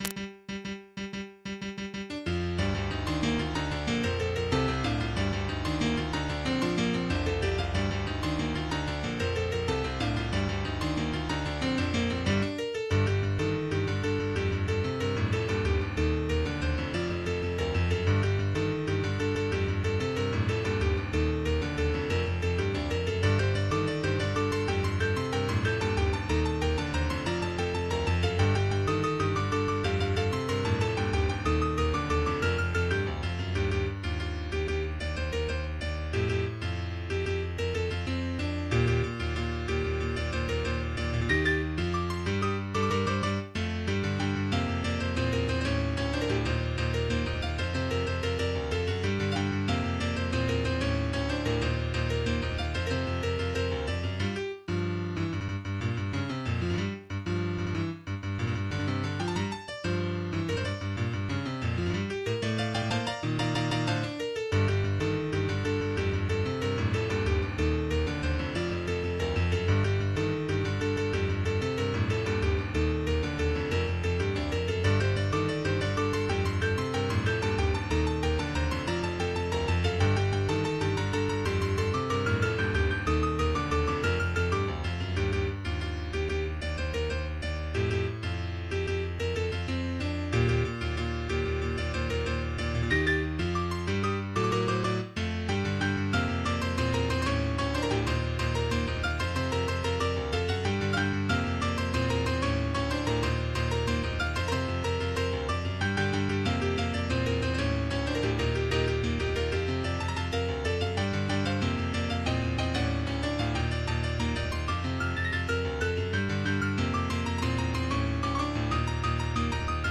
MIDI 27.95 KB MP3 (Converted) 3.06 MB MIDI-XML Sheet Music
speedy piano light rock song